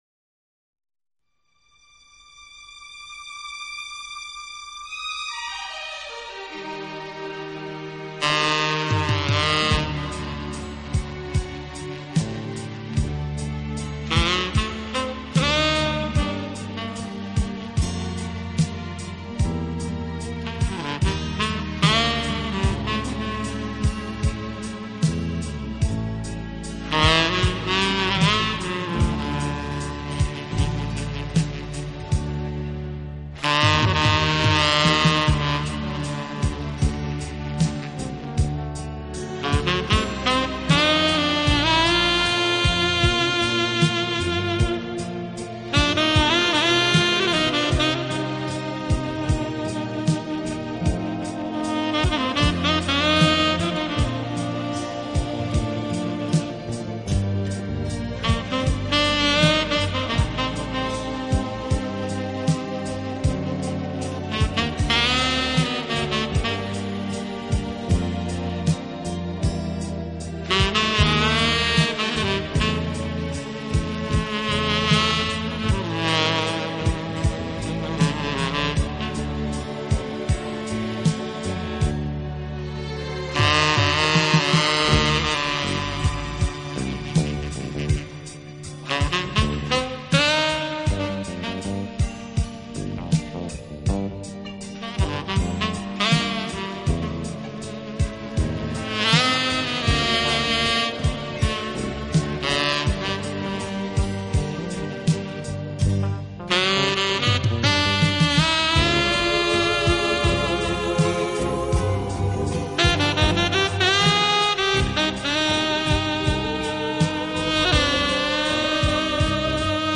音乐类型：Instrumental
一直以来的灼热萨克斯演奏风格。